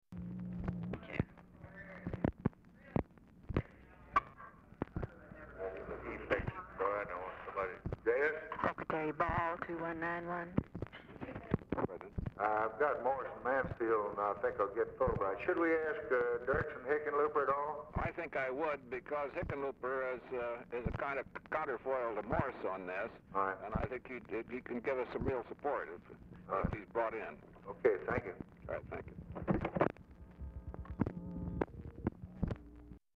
Telephone conversation # 442, sound recording, LBJ and GEORGE BALL, 12/12/1963, 12:01PM | Discover LBJ
Format Dictation belt
Location Of Speaker 1 Oval Office or unknown location